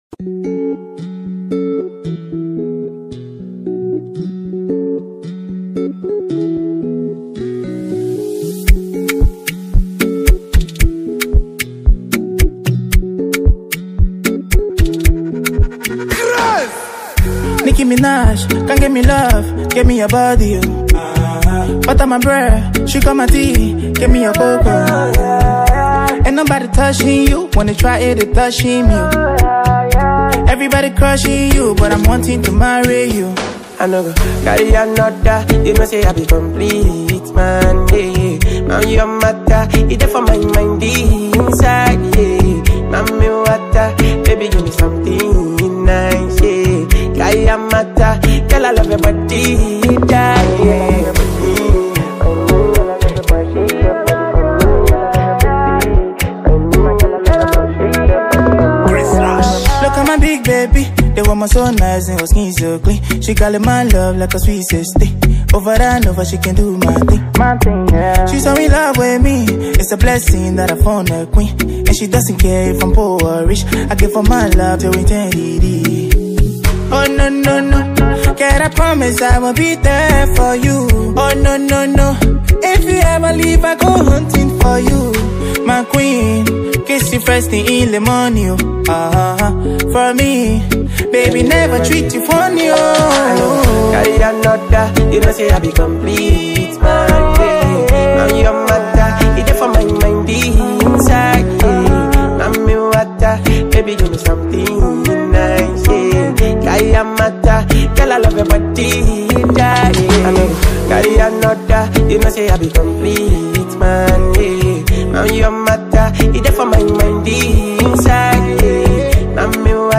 Secular Songs
Afropop